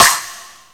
INSNAREFX9-R.wav